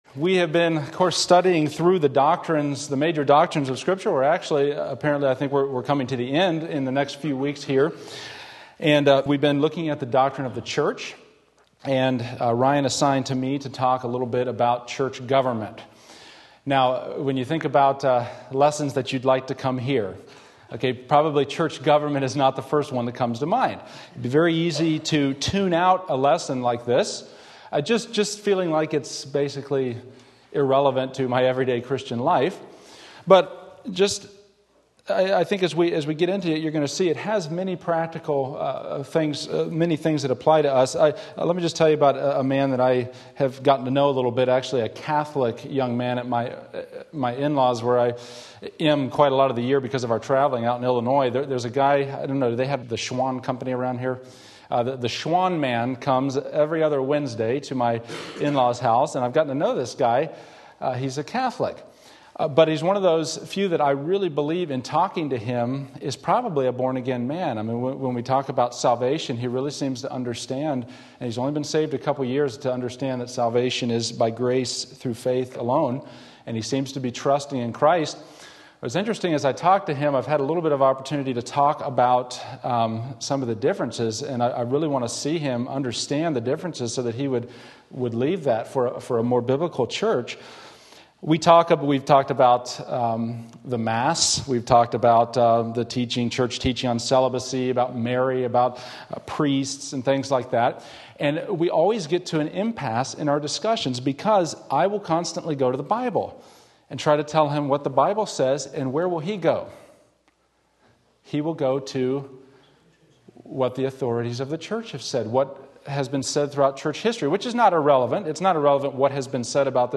Church Government Various Texts Sunday School